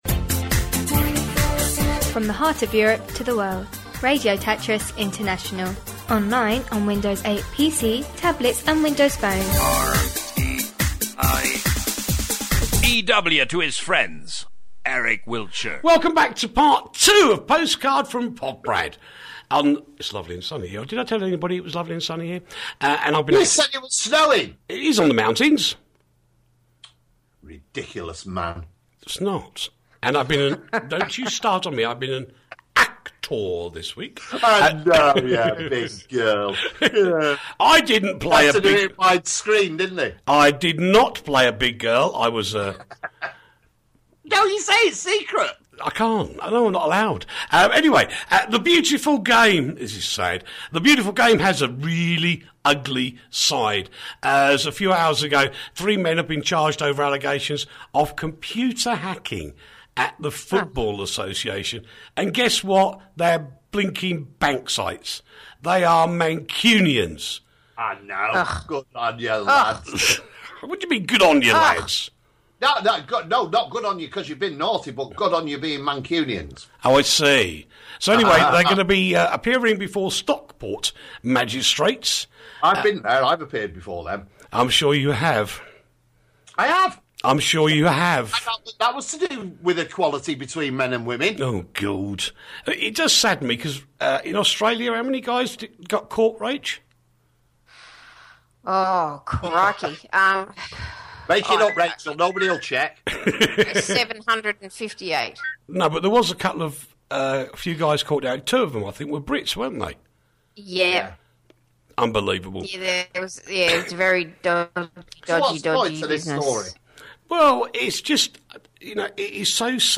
the alternative news show